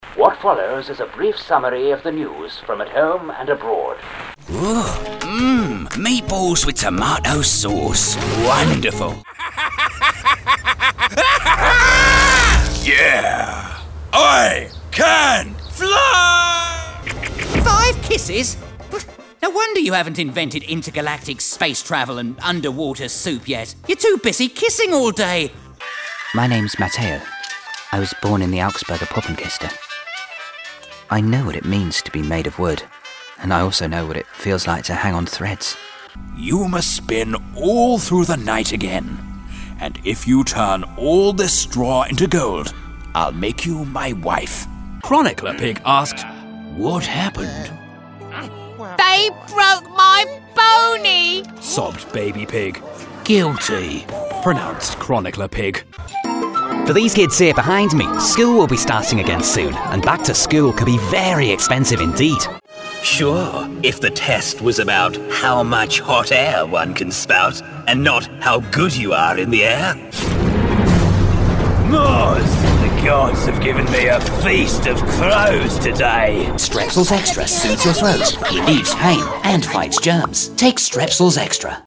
20 Okt Character Voice Medley